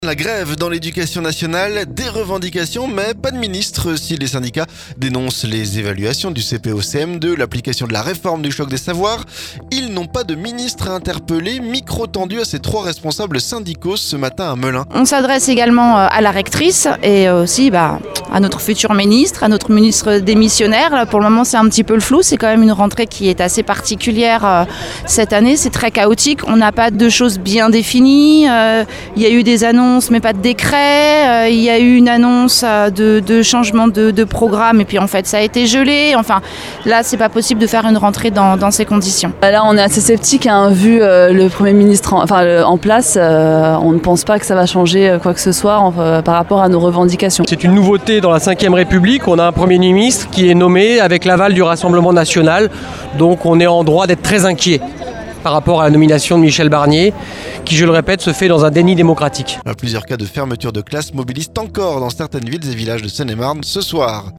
Micro tendu à ces trois responsables syndicaux ce mardi à Melun.